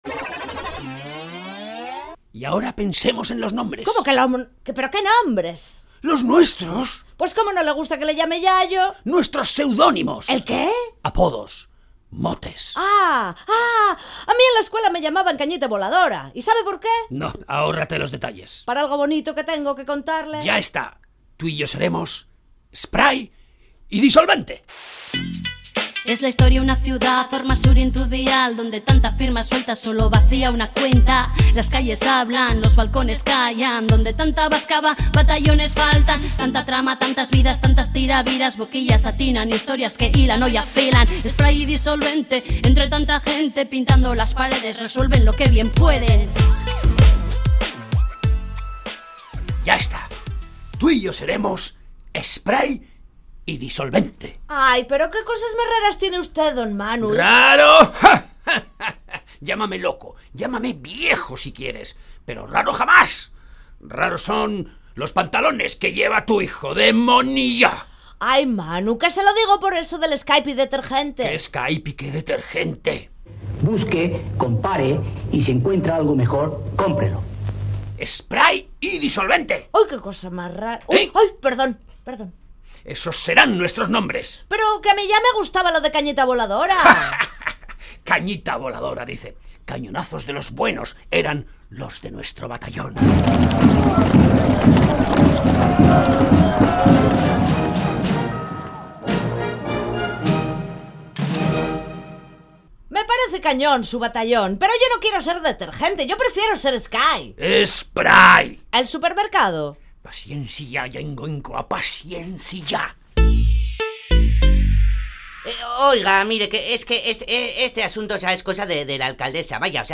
Sexta entrega de la Radio-Ficción "Spray & Disolvente"